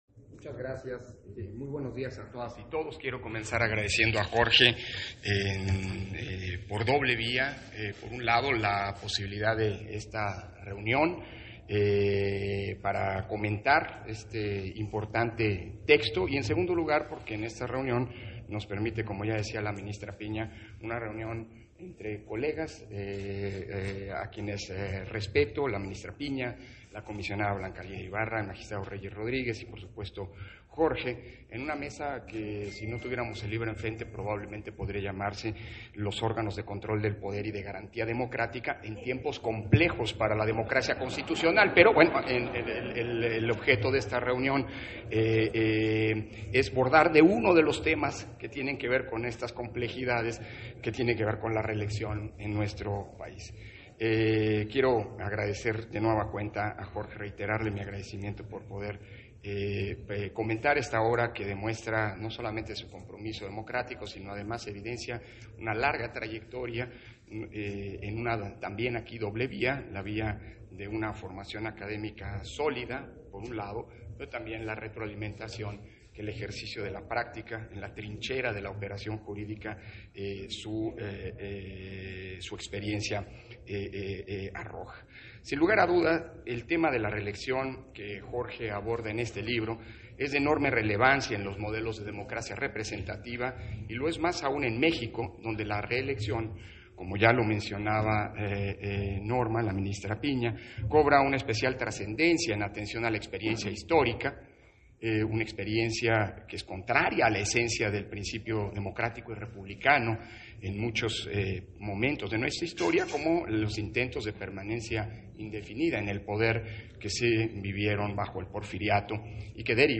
281121_AUDIO_INTERVENCIÓN-CONSEJERO-PDTE.-CÓRDOVA-PRESENTACIÓN-DE-LA-OBRA-LA-REELECCIÓN-EN-MÉXICO - Central Electoral